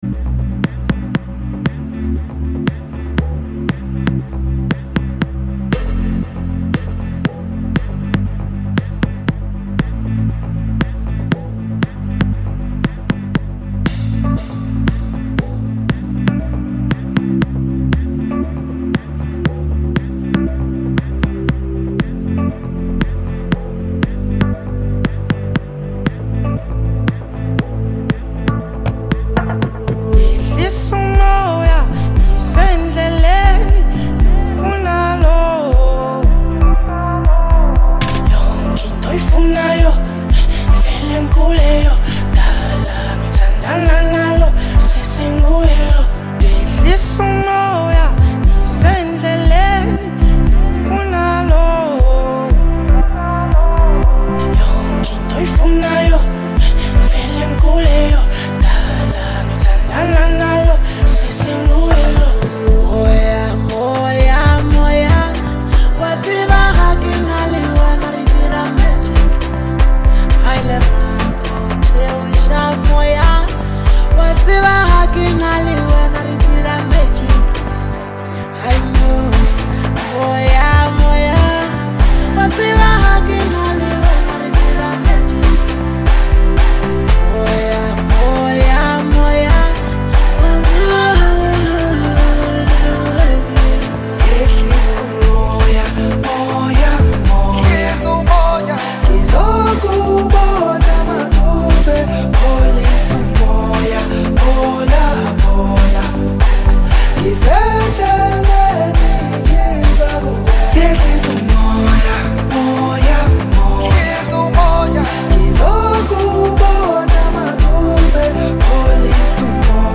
Award winning singer